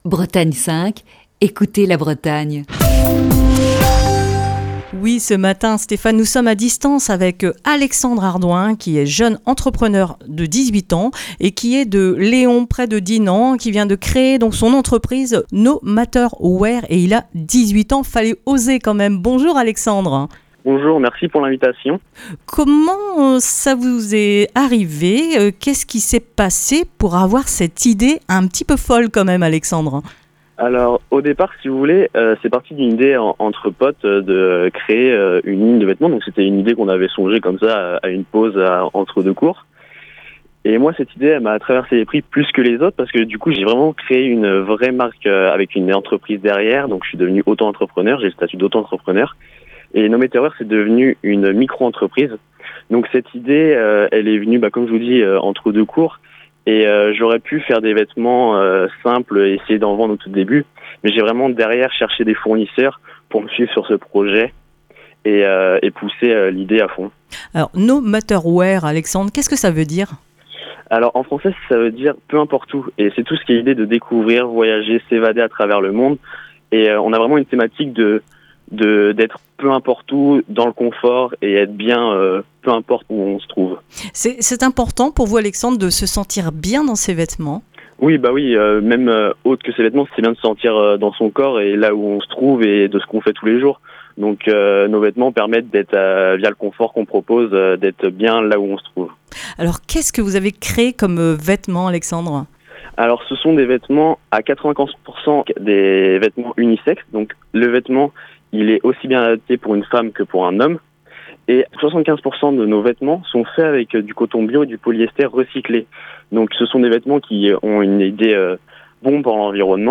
Ce matin dans le coup de fil du matin